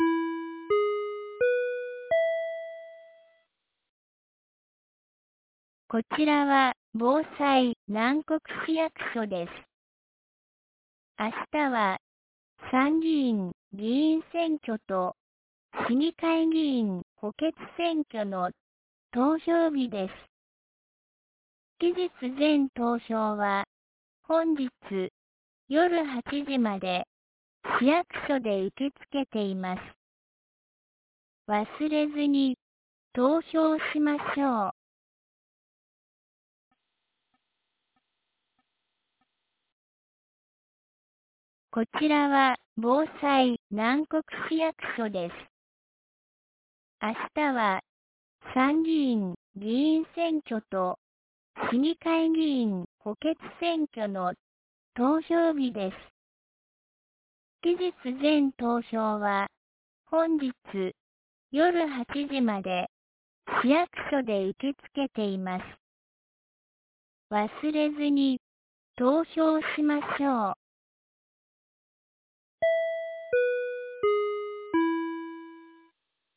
2025年07月19日 10時01分に、南国市より放送がありました。